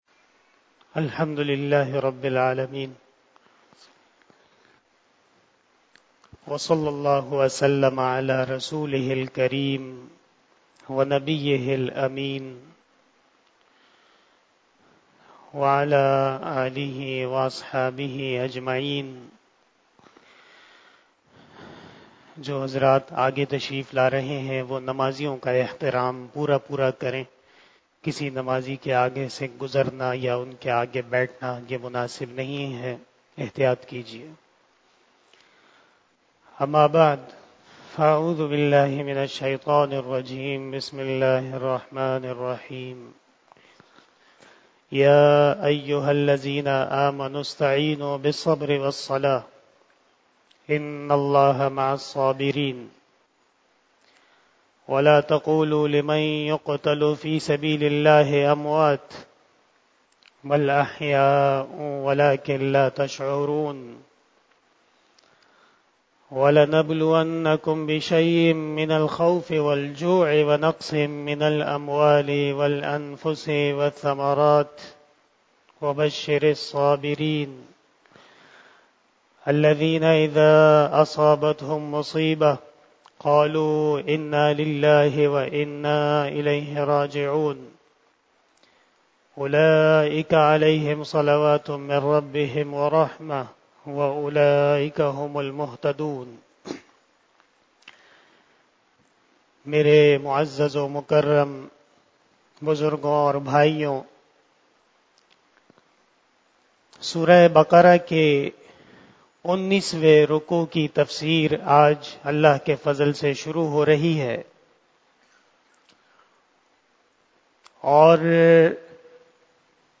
بیان شب جمعه المبارک